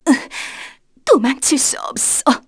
Artemia-Vox_Dead_kr.wav